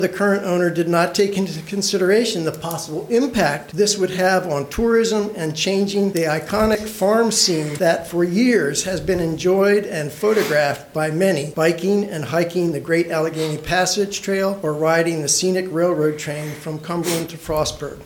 During last night’s Allegany County Commissioner’s meeting, residents of Cash Valley showed concern regarding the former Helmstetter farm becoming a solar project.